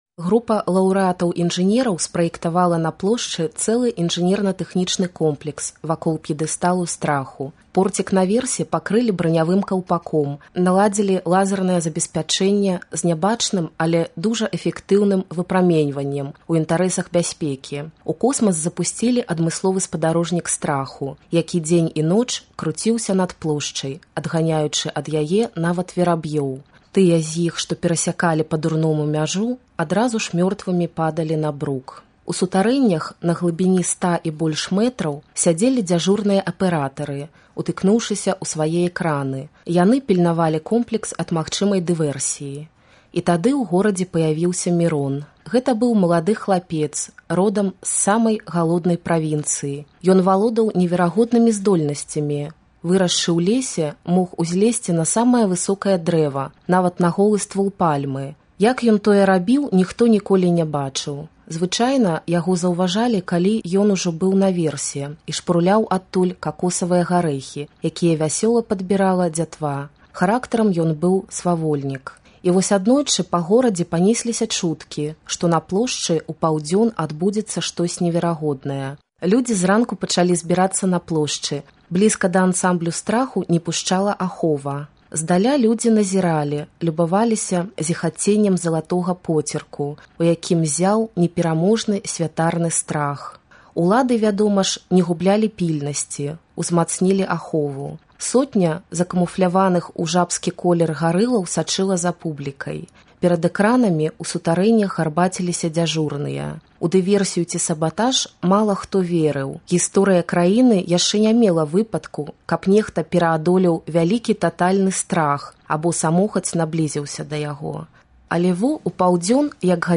Штодня на працягу чэрвеня палітычныя вязьні, іх родныя і блізкія чытаюць быкаўскія радкі.